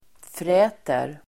Uttal: [fr'ä:ter]